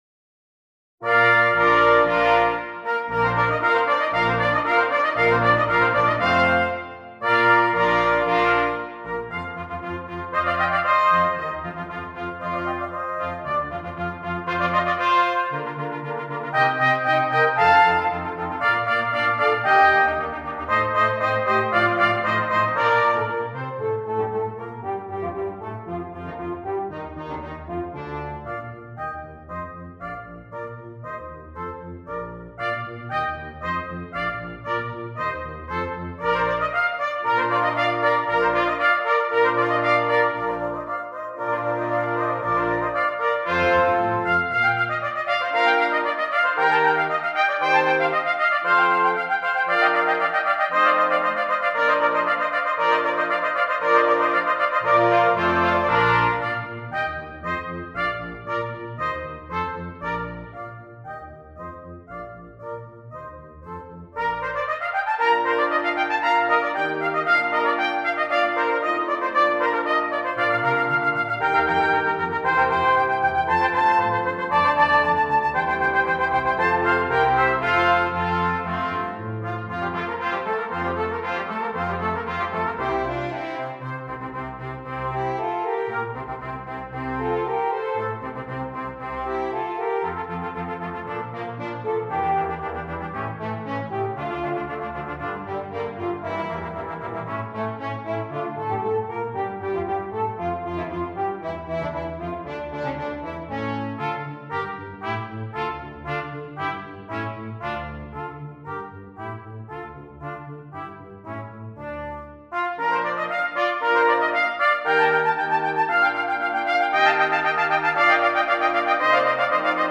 Double Brass Quintet